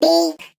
Sfx_tool_spypenguin_vo_horn_01.ogg